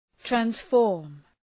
{træns’fɔ:rm}